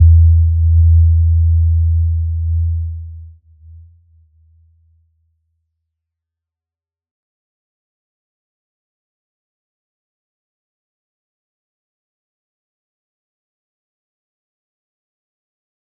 Little-Pluck-E2-p.wav